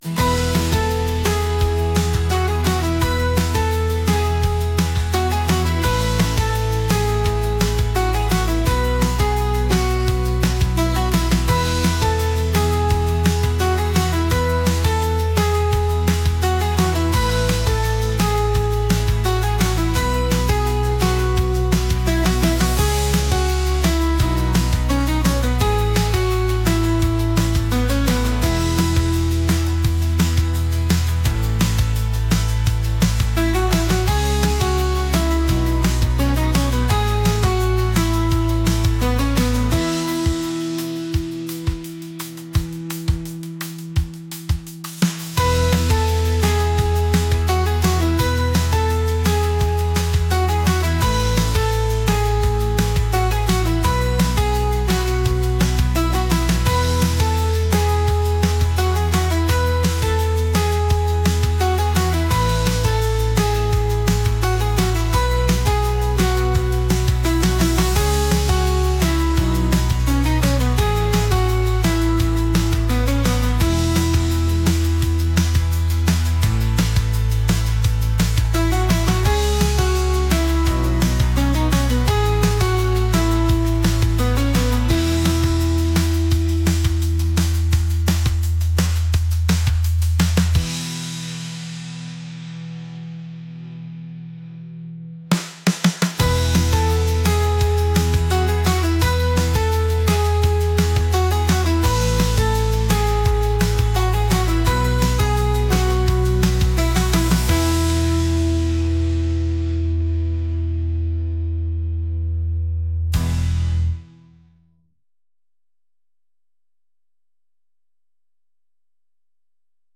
energetic | pop